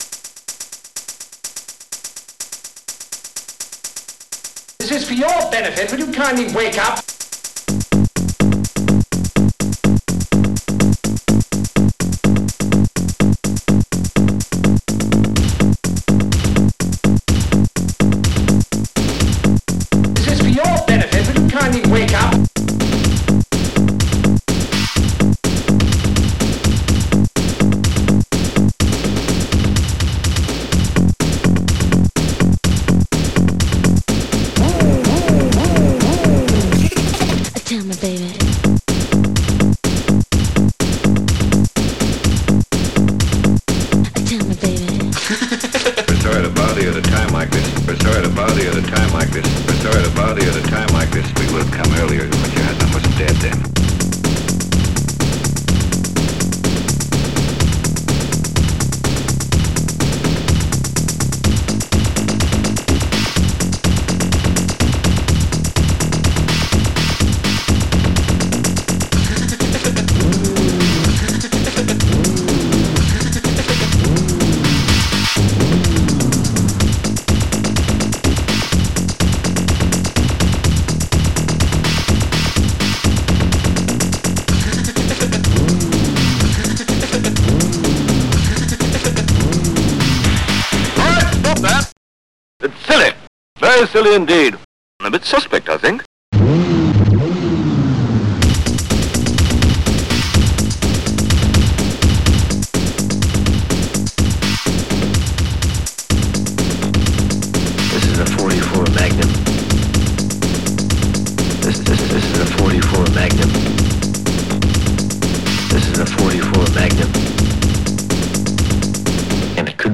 Snare
Bass
Cymbal
Laser FX
(Laughter)
Vroom